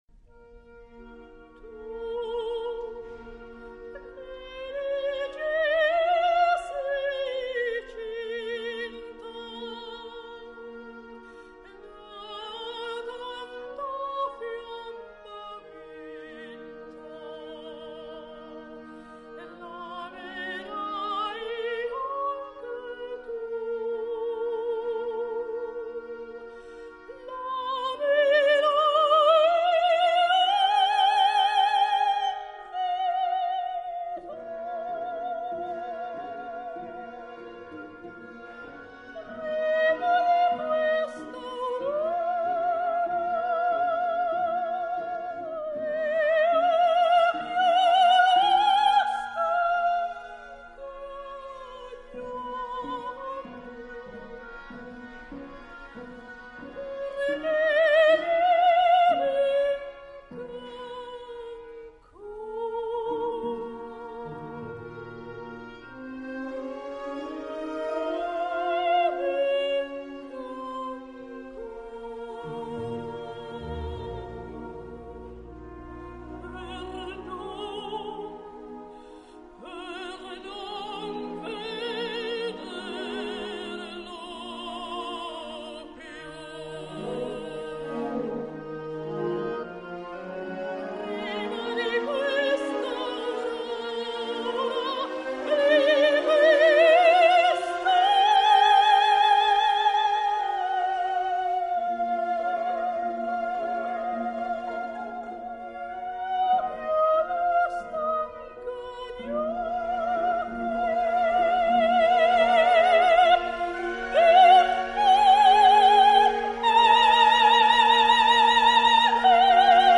08.1972 [Studio] CD DECCA [ES&DF: unsere Referenzaufnahme]
Dir.: Z.Mehta - LPO
Liù [Sopran]